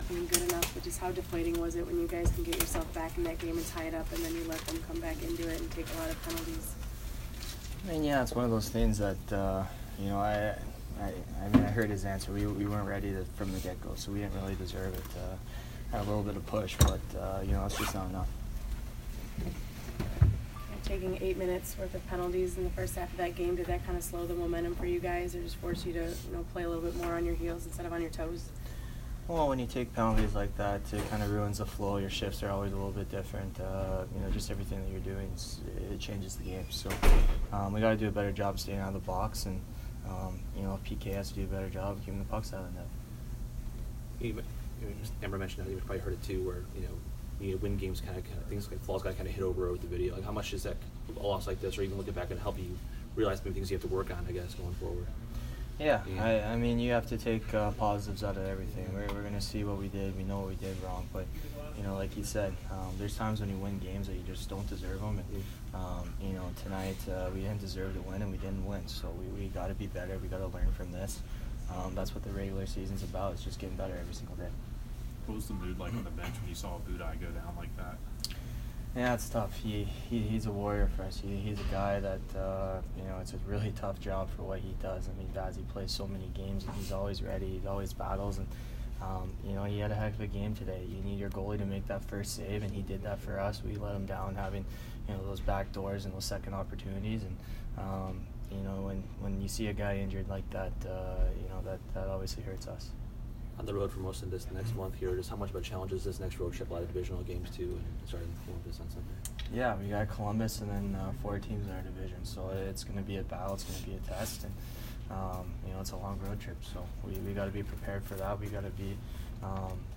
Tyler Johnson Post-Game 12/29